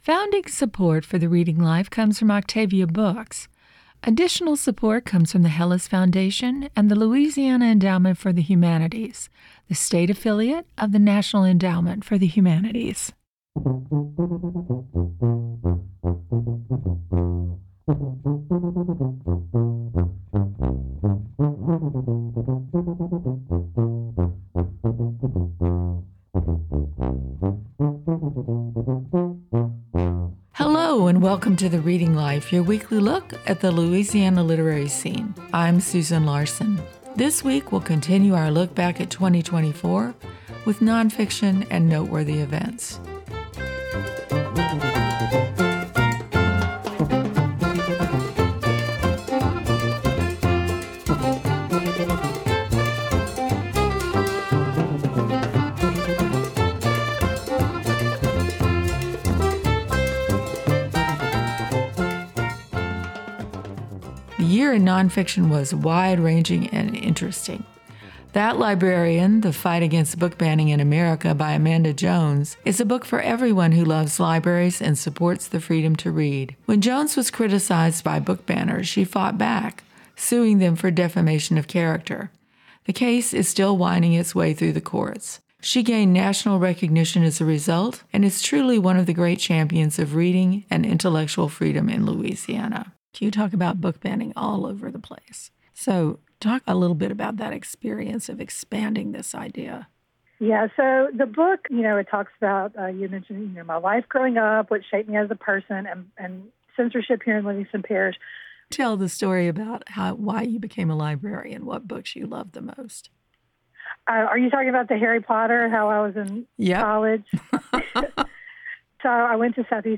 Hear celebrated and up-and-coming authors read excerpts from new books